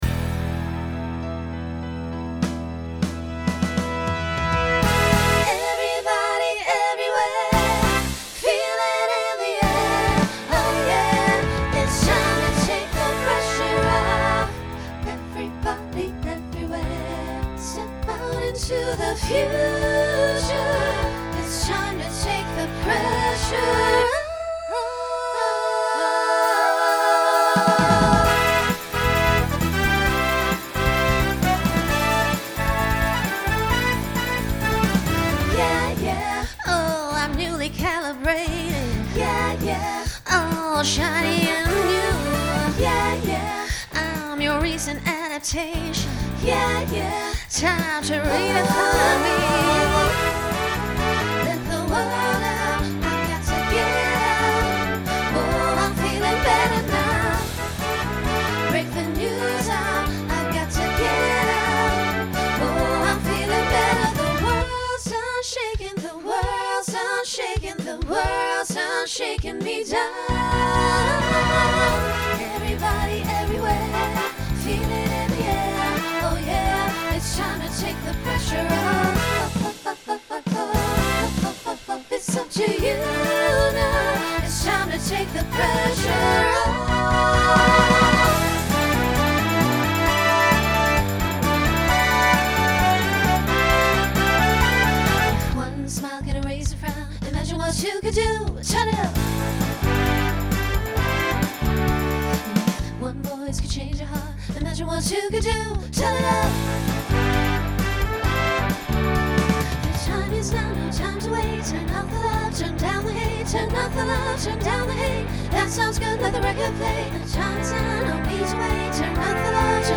Pop/Dance , Rock
Voicing SSA